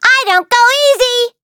Taily-Vox_Skill5_a.wav